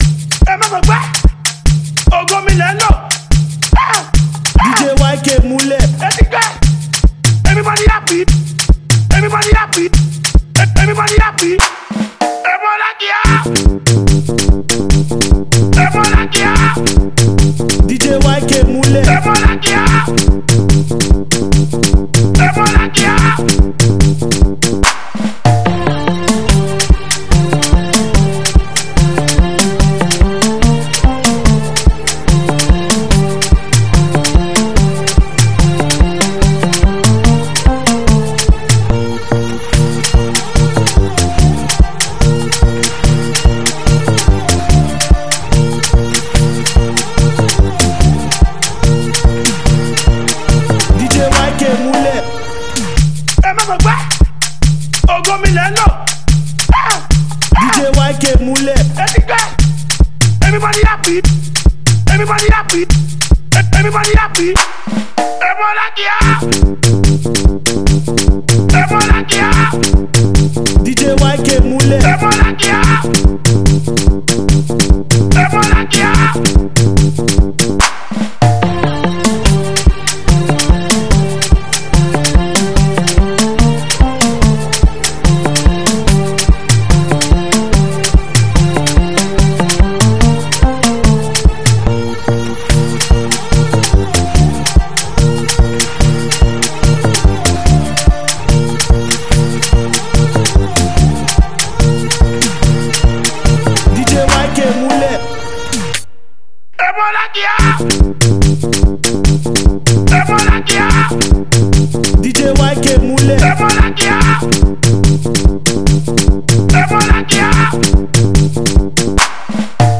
thrilling new gbedu song